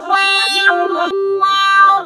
VOX FX 8  -L.wav